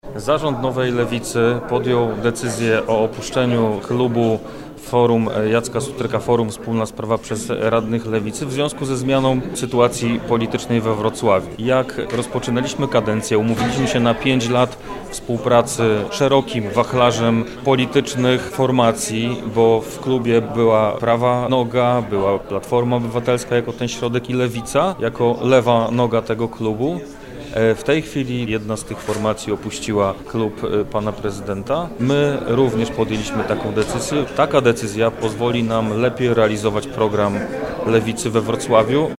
-Decyzja motywowana jest politycznie – tłumaczy radny Dominik Kłosowski.